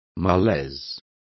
Complete with pronunciation of the translation of malaise.